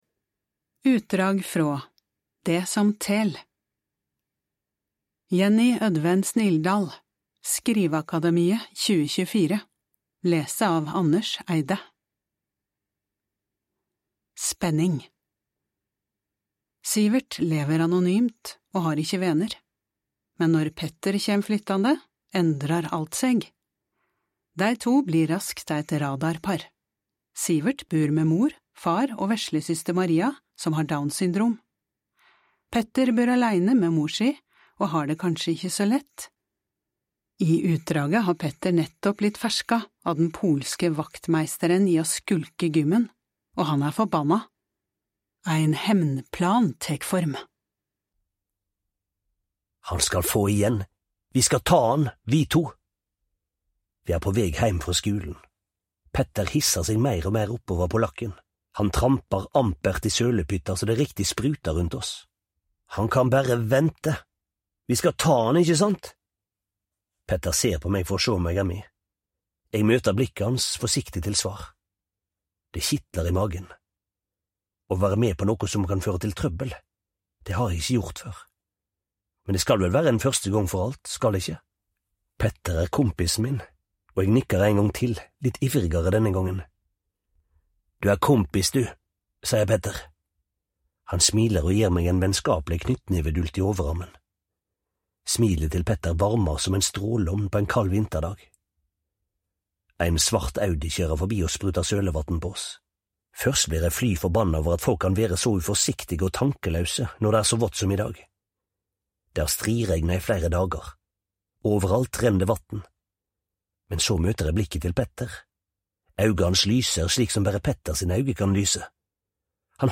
Lån heile lydboka frå Tibi! Sivert lever anonymt og har ikkje vener, men når Petter kjem flyttande, endrar alt seg.